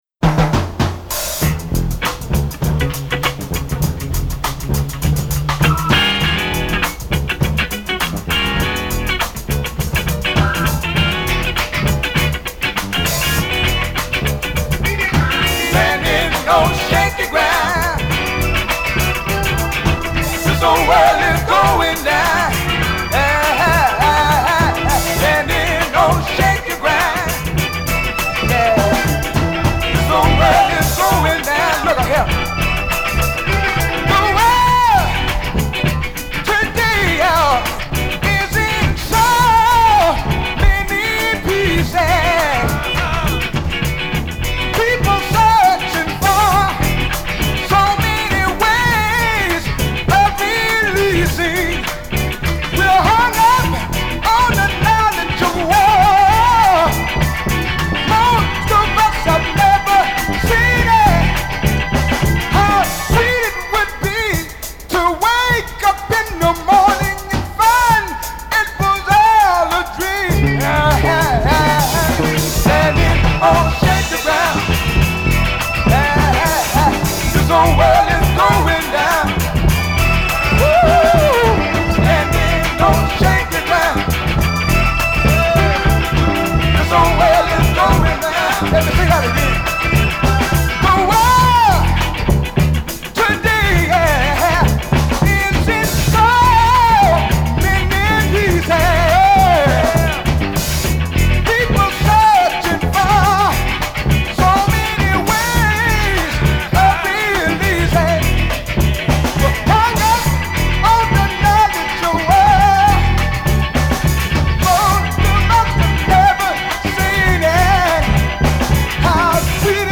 Heavyweight (mid 70’s) funk critique of this ‘Old World’.